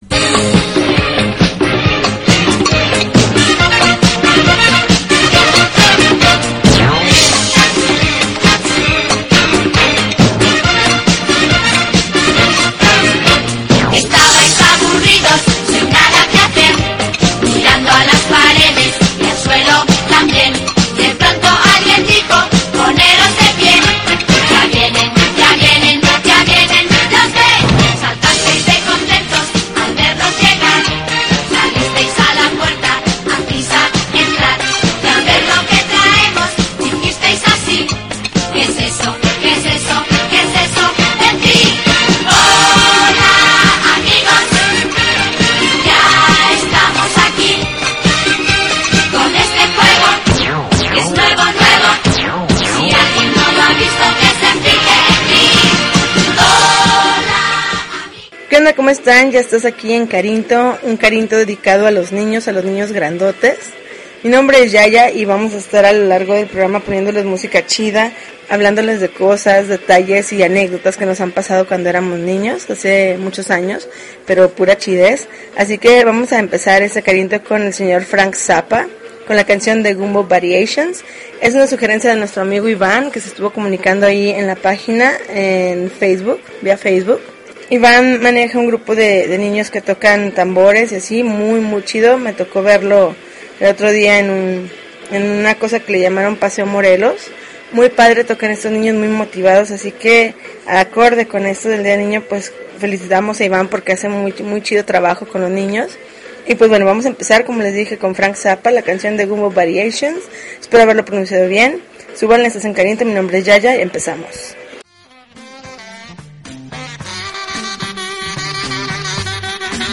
April 29, 2013Podcast, Punk Rock Alternativo